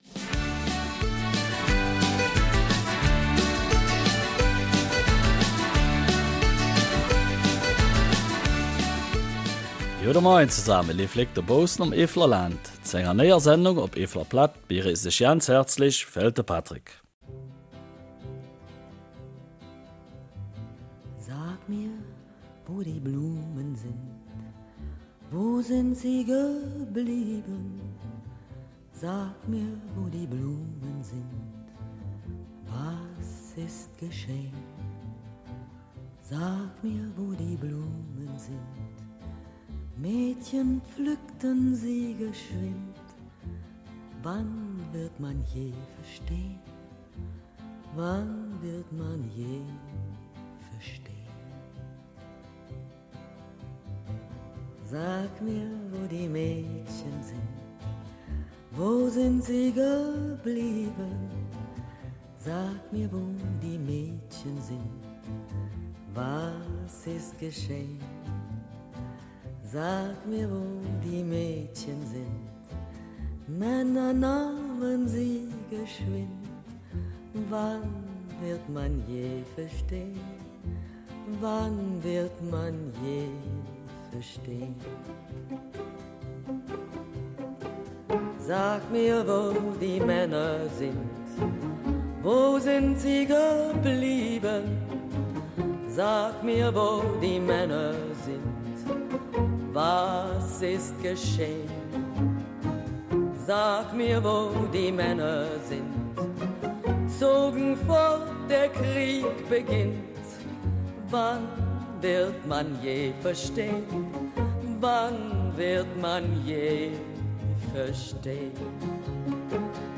Eifeler Mundart: ''Kleines Archivarium'' erinnert an Ardennenoffensive